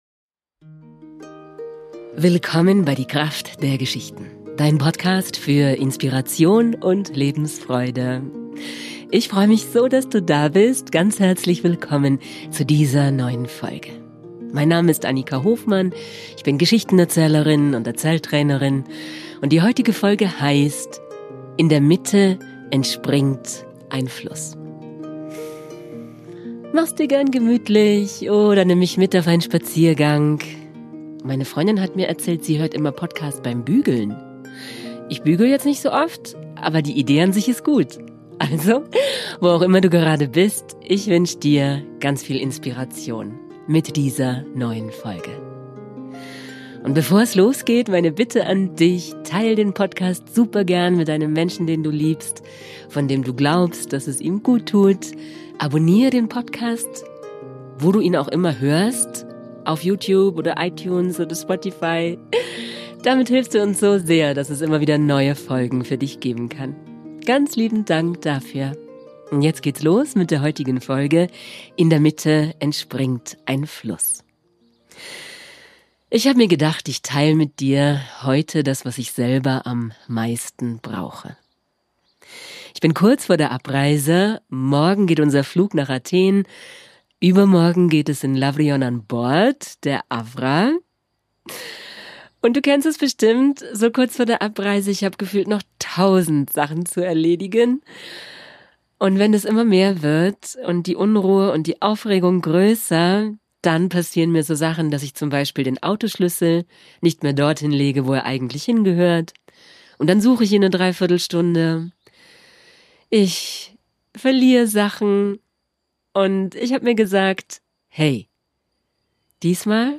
Besonders wenn wir viel zu tun haben brauchen wir Momente der Ruhe. Heute bekommst Du eine Meditation und den Anfang von der Geschichte, die ich an Bord der Avra in Griechenland der Crew erzähle.